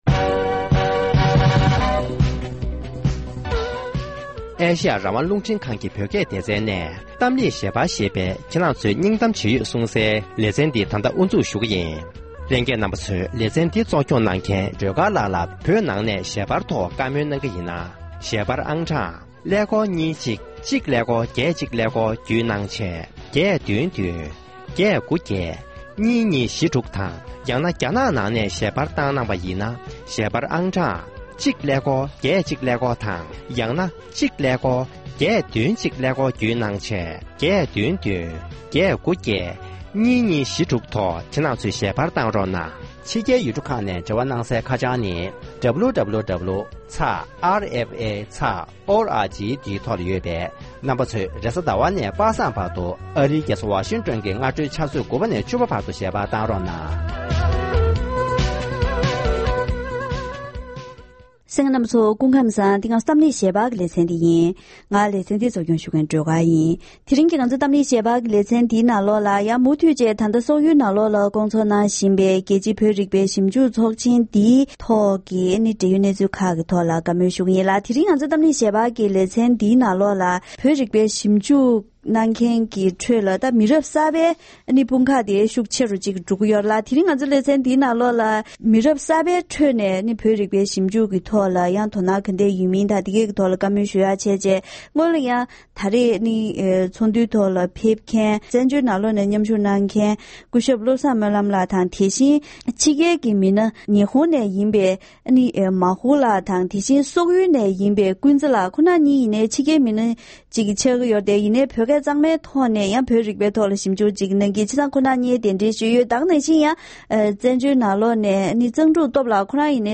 ༄༅༎དེ་རིང་གི་གཏམ་གླེང་ཞལ་པར་ལེ་ཚན་ནང་སོག་ཡུལ་དུ་སྐོང་ཚོགས་གནང་བཞིན་ཡོད་པའི་རྒྱལ་སྤྱིའི་བོད་རིག་པའི་ཚོགས་ཆེན་ཐོག་བརྗོད་གཞི་གསར་པ་དང་མི་རབས་གསར་པའི་ཁྲོད་ནས་མཉམ་ཞུགས་གནང་མཁན་མང་དུ་འགྲོ་ཡི་ཡོད་པ་སོགས་ཀྱི་ཐད་ཚོགས་ཞུགས་མི་སྣ་ཁག་ཅིག་ལྷན་བཀའ་མོལ་ཞུས་པ་ཞིག་གསན་རོགས་གནང་།།